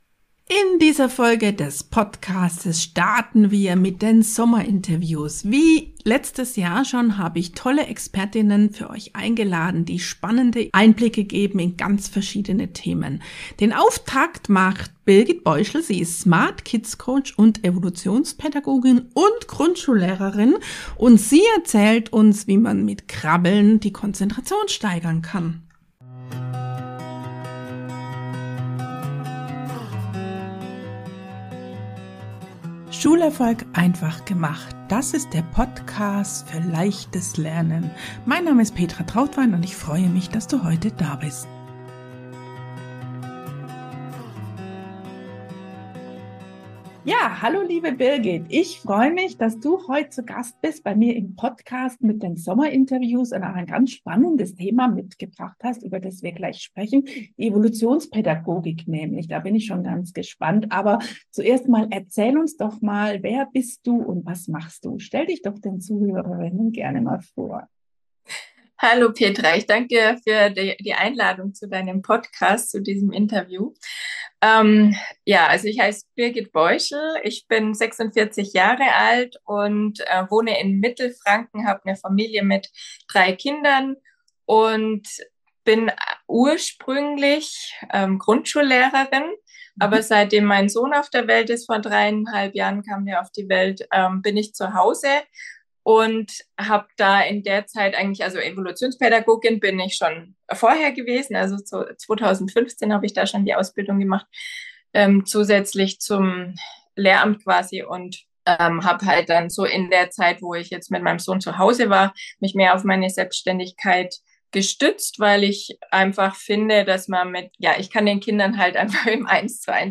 Heute starten wir in die Sommerinterviews mit unterschiedlichen spannenden Expertinnen.